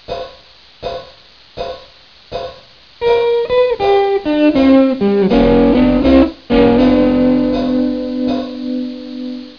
Некоторые из моих любимых наигрышей повторяют стиль буги-вуги. Два из них показаны в Примерах 4 и 5.